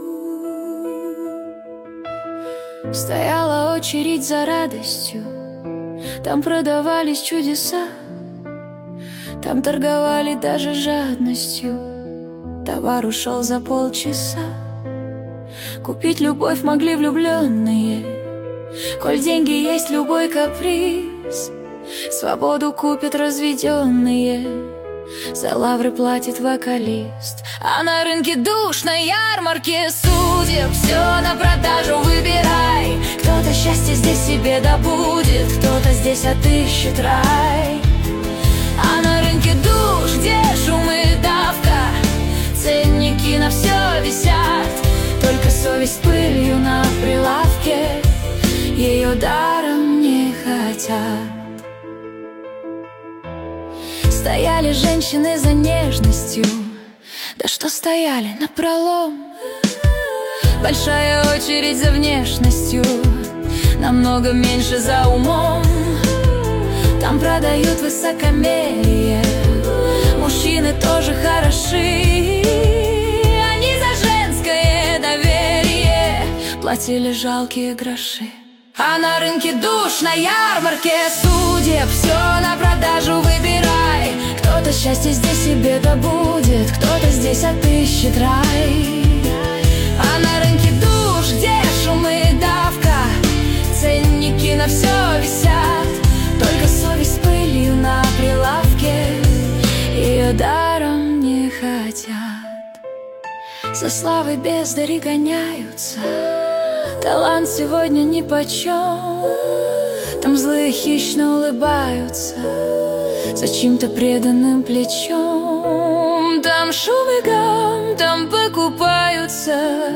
Качество: 320 kbps, stereo
Песни Суно ИИ, Нейросеть Песни 2025